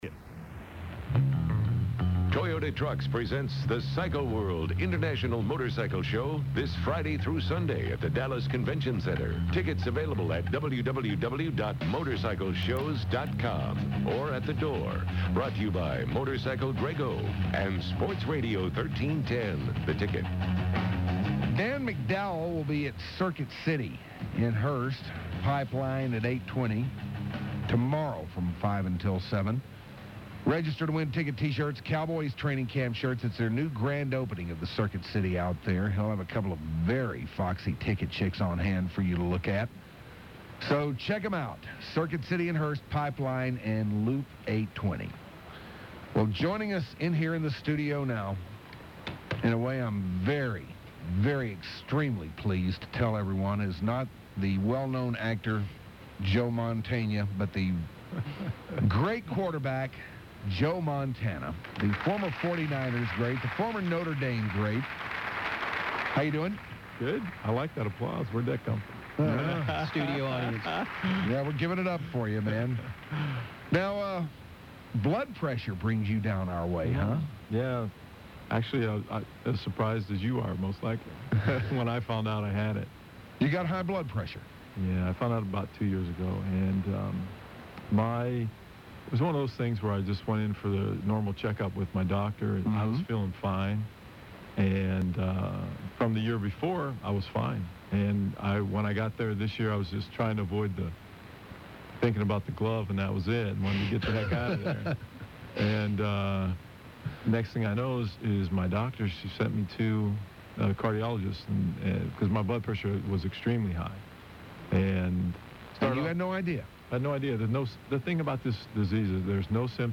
The Hardliners interview Joe Montana